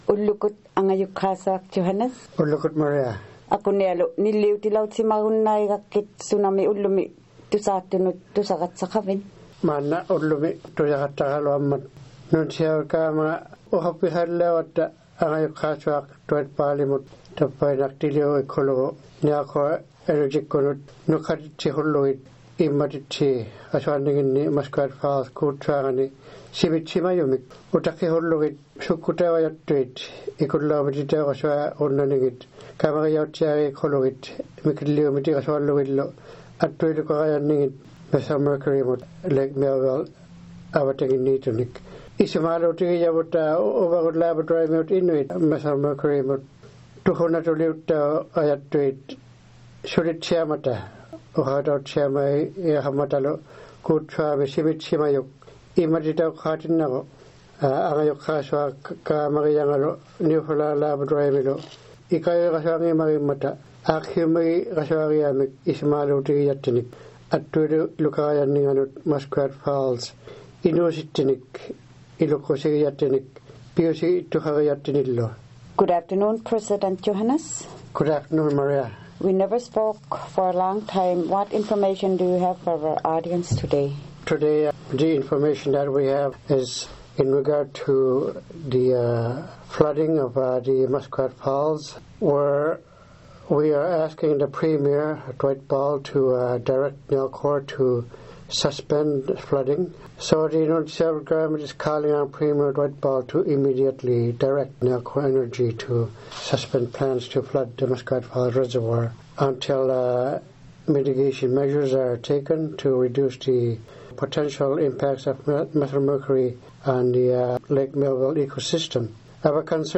We spoke to President Johannes Lampe about this regarding to the flooding of Muskrat Falls.